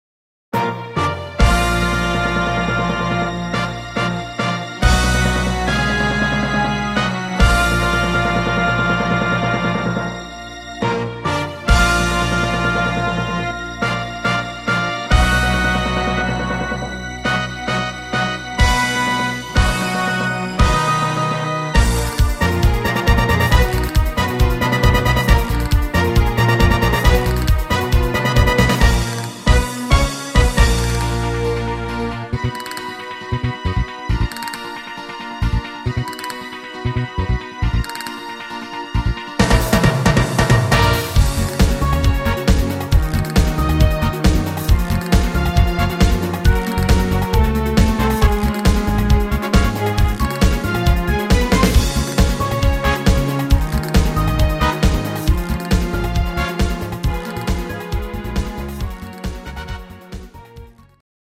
Rhythmus  Disco
Art  Pop, Oldies, Weibliche Interpreten